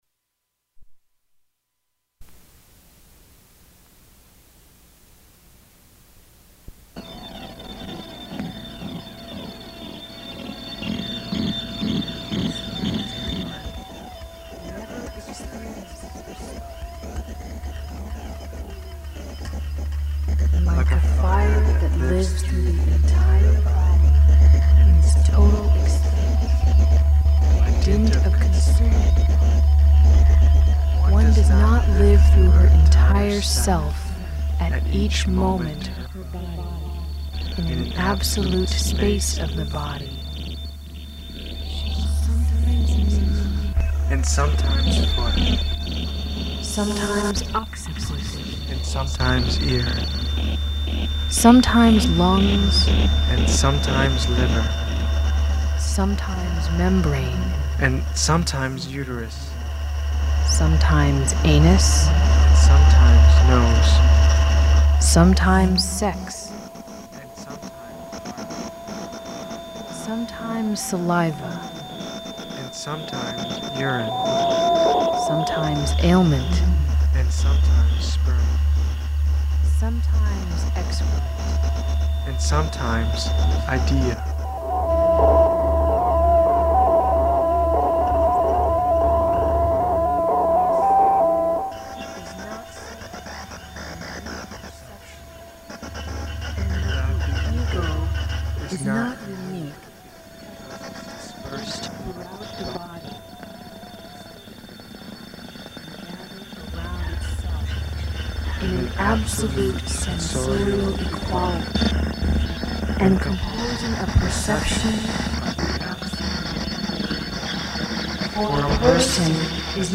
Gorgeous Fever, 1994, Chicago, Randolph Street Gallery, multimedia performance, "Body Report/Telemetry" audio for performance
Cassette